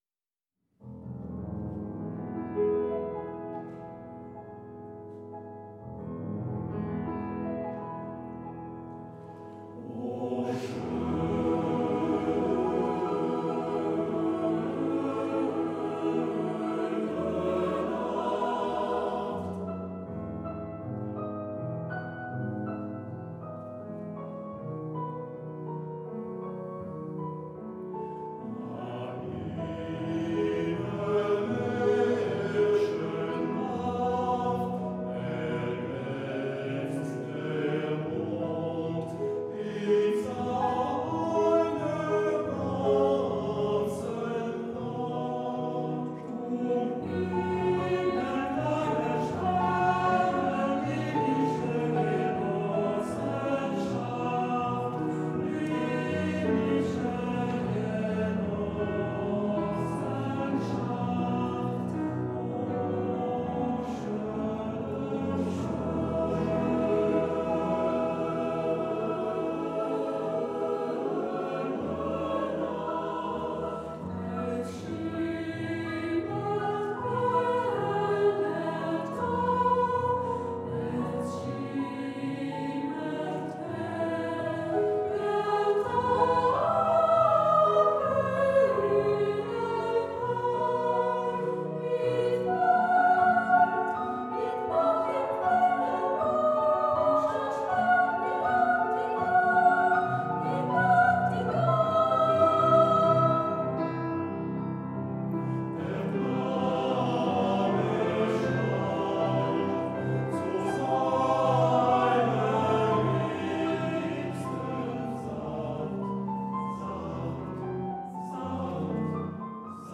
Pour travailler "O sch�ne nacht", t�l�chargez la version de l’ensemble vocal de saint-quentin-en-yvelines en cliquant sur l’ic�ne ci-dessous symbolis�e par un Haut-Parleur (*).
Johannes Brahms, Opus 92 n°1 : O sch�ne nacht par l’Ensemble Vocal de Saint-Quentin-en-Yvelines.
Ensemble_Vocal_SQY_-_O_schone_Nacht.mp3